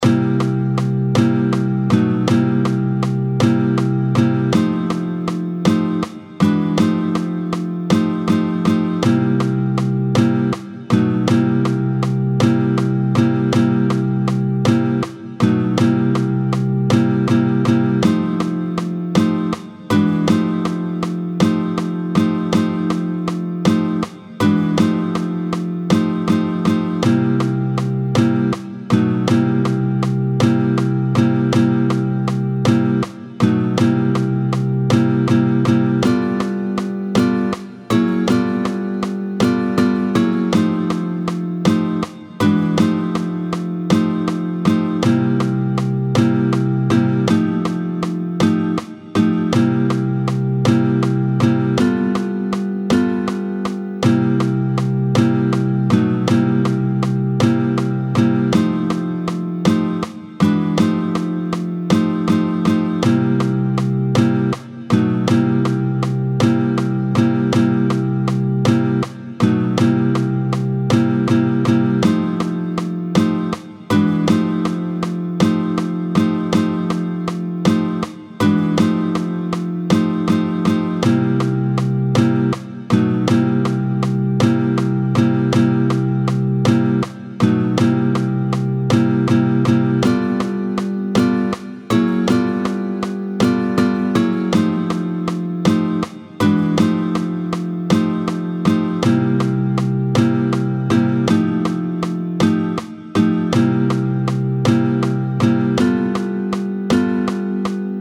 29-04 Blues ternaire en Do, tempo 80